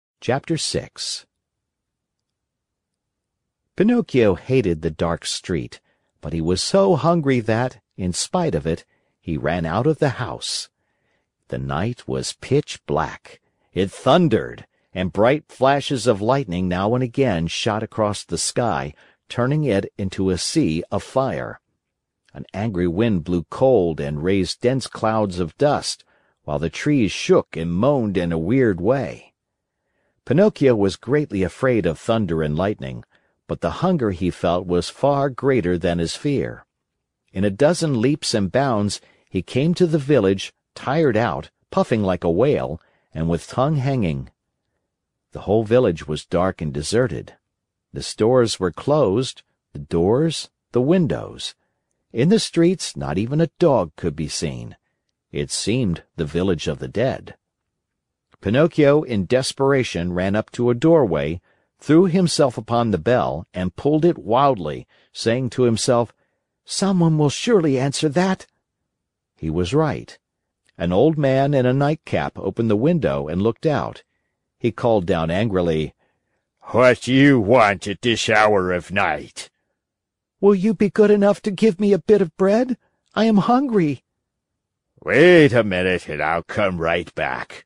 在线英语听力室木偶奇遇记 第12期:鲸鱼一样大喘气的听力文件下载,《木偶奇遇记》是双语童话故事的有声读物，包含中英字幕以及英语听力MP3,是听故事学英语的极好素材。